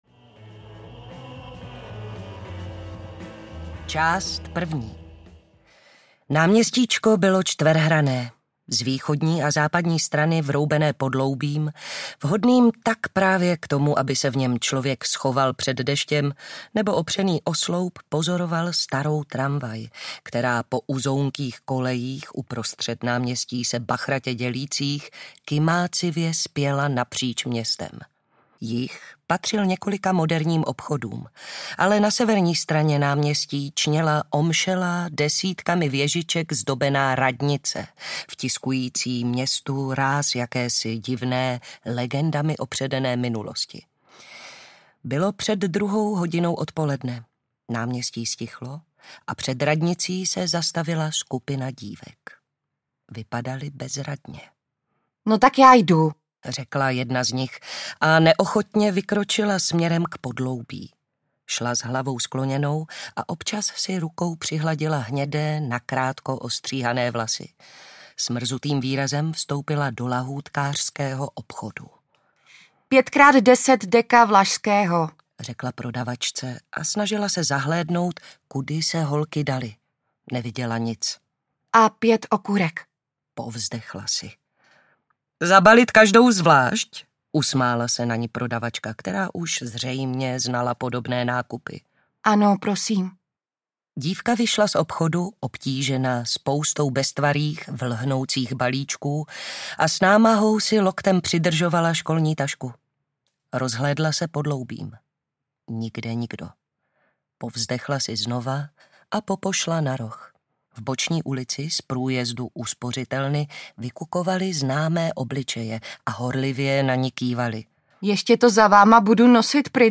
Audio knihaPět holek na krku
Ukázka z knihy